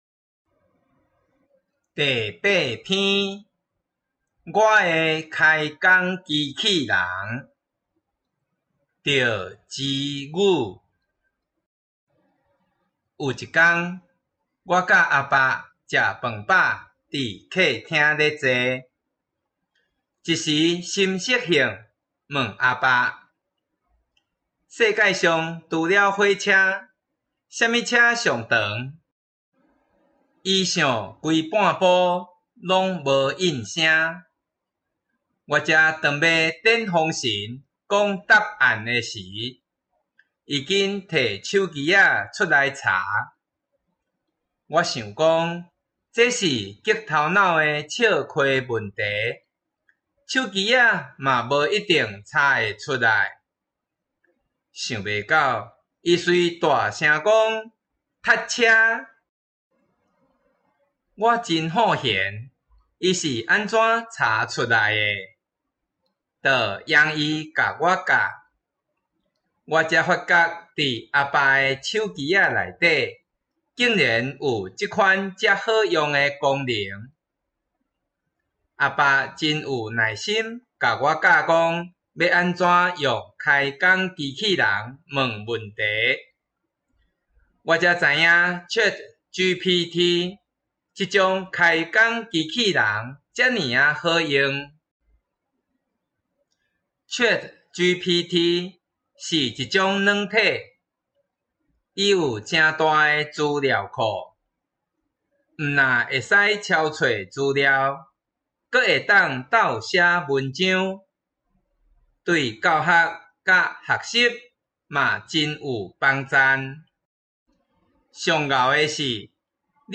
114臺灣台語朗讀音檔2-我的開講機器人.m4a